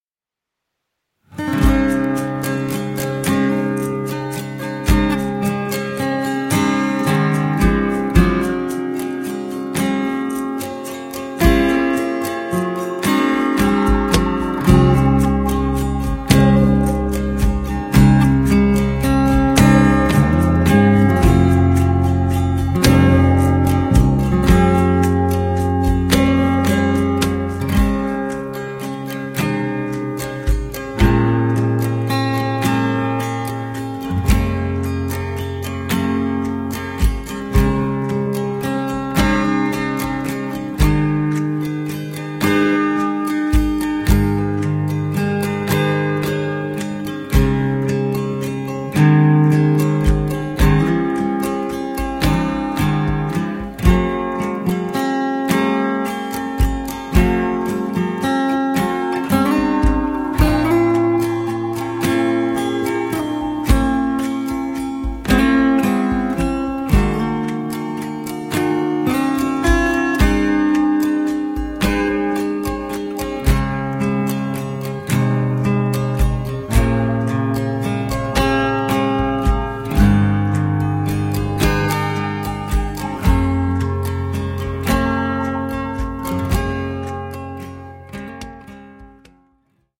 Die schönsten Gute-Nacht-Lieder für Kinder.